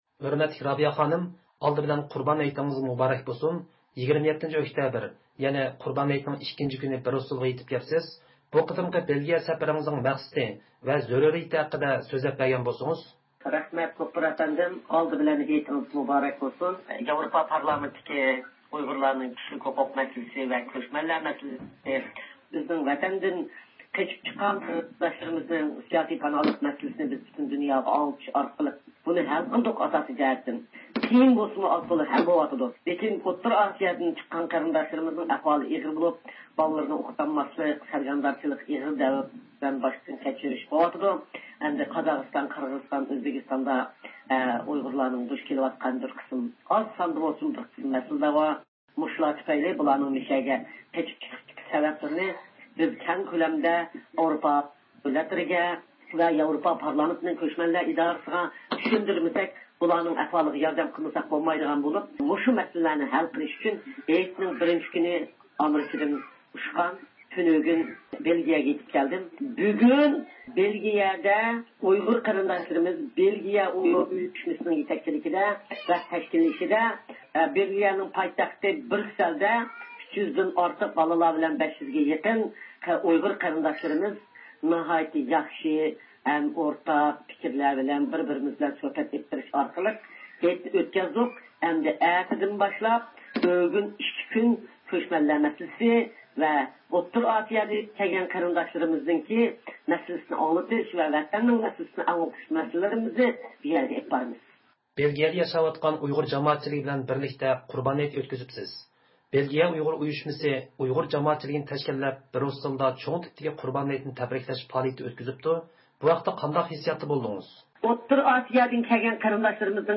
بىز رابىيە قادىر خانىمنىڭ بۇ قېتىمقى بېلگىيە زىيارىتى ھەققىدە تەپسىلىي مەلۇماتقا ئېرىشىش ئۈچۈن تېلېفون سۆھبىتى ئېلىپ باردۇق.